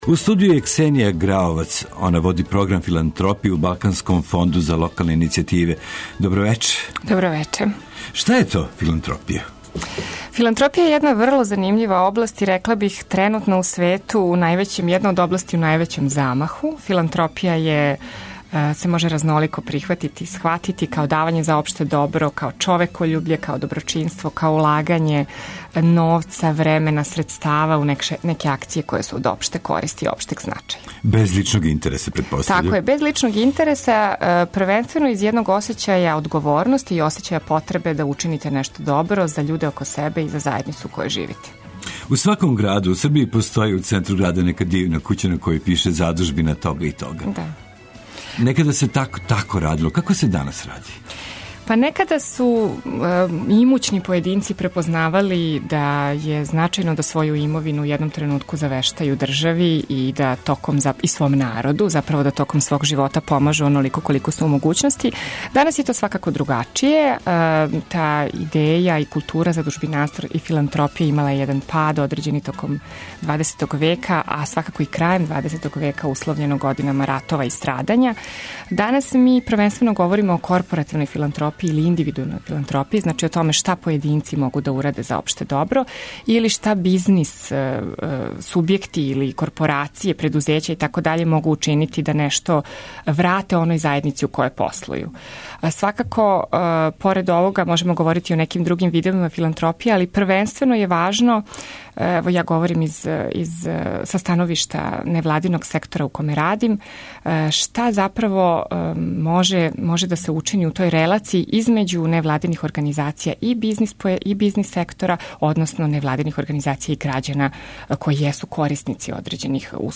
Чућемо и представнике неколико фондација и задужбина, које делују широм Србије.